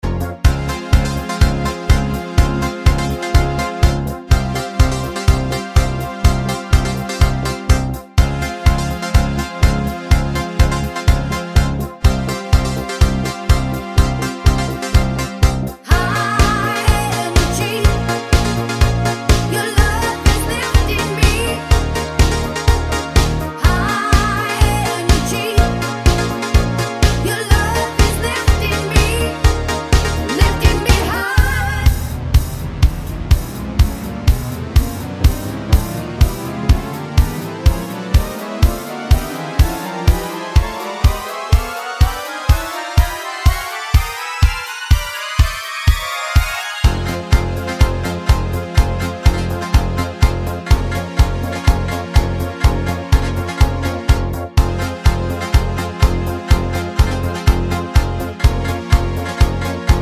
no Backing Vocals Dance 4:02 Buy £1.50